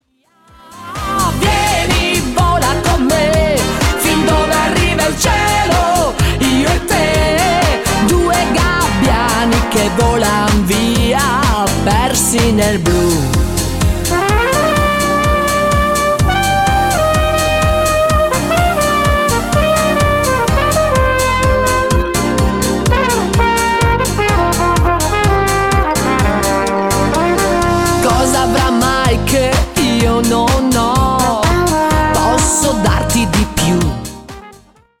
CHA CHA CHA  (3.21)